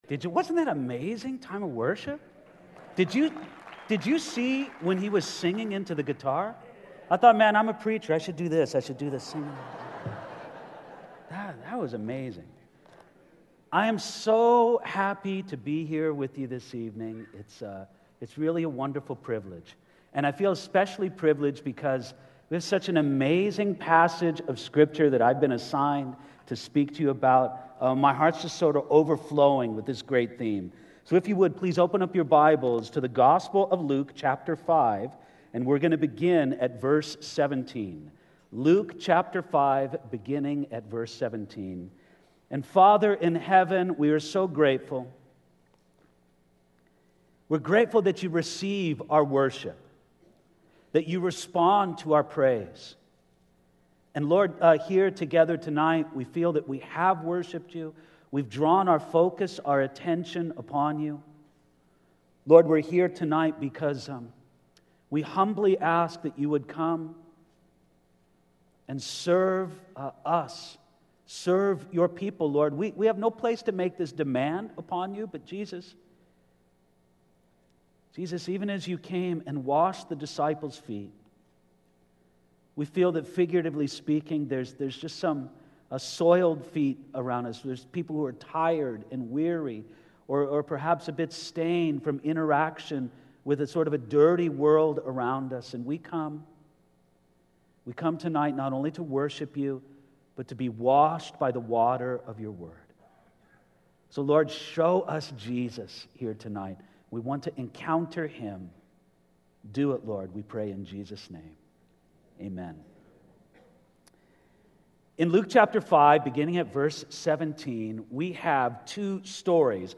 Southwest Pastors and Leaders Conference 2014